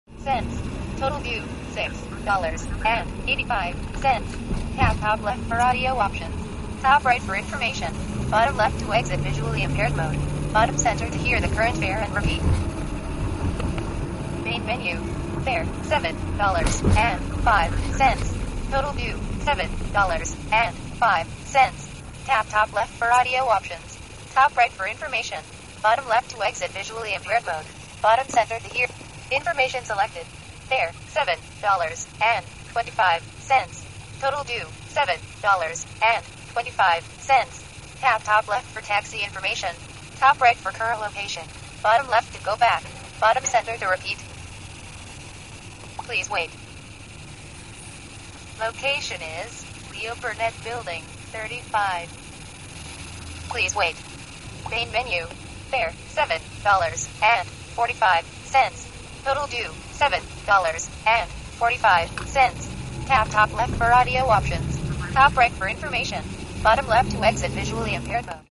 audio of the taxi ride.
Taxi.mp3